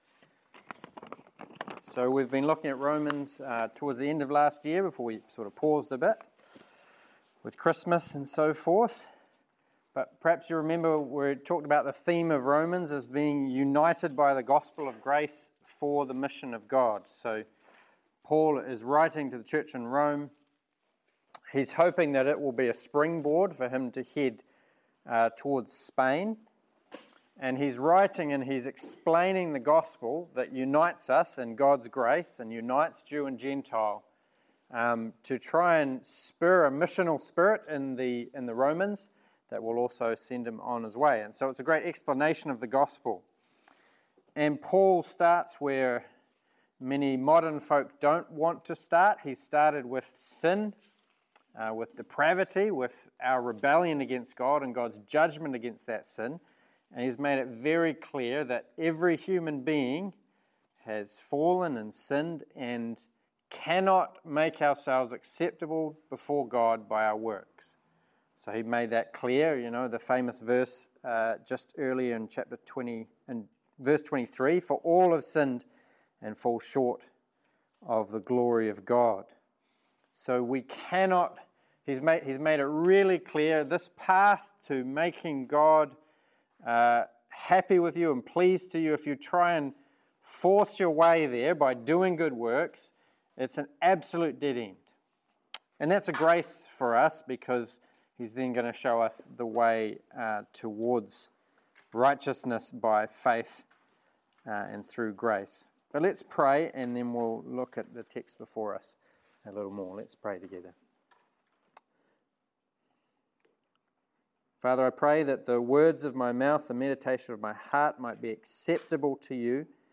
Romans 3:27-4:12 Service Type: Sermon This week we continue our series in Romans.